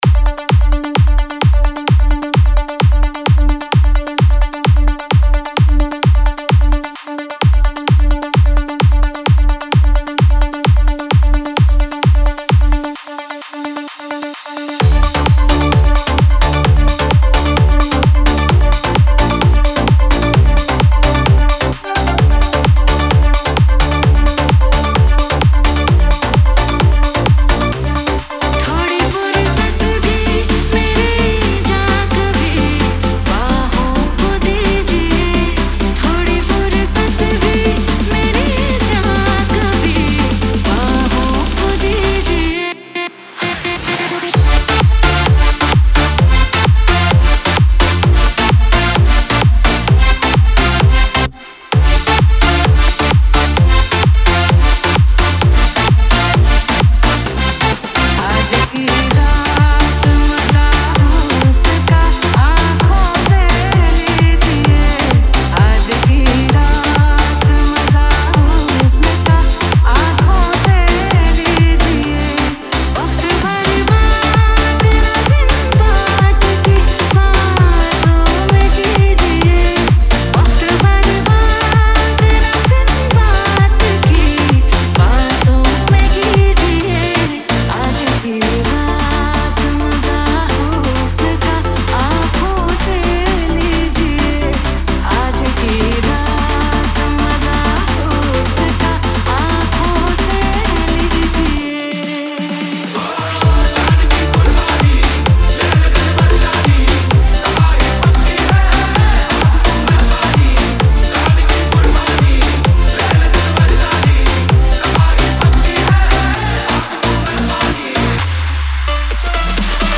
Category : Club